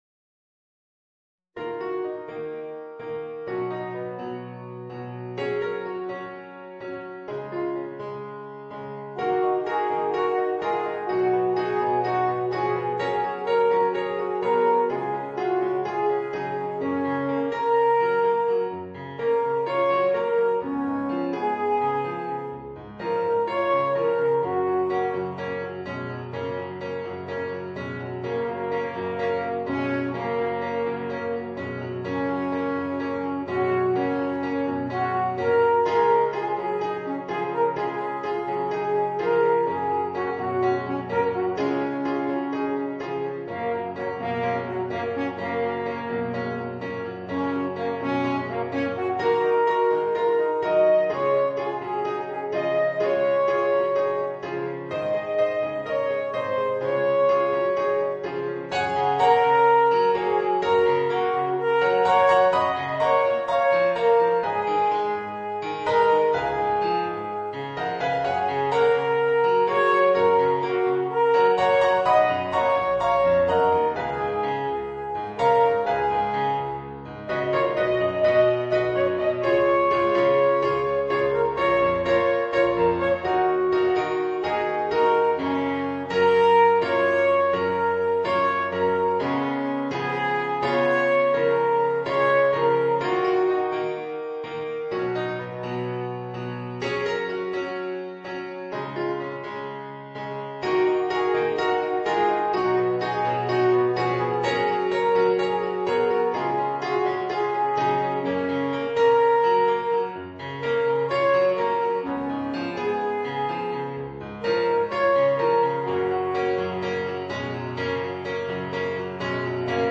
Voicing: Alphorn w/ Audio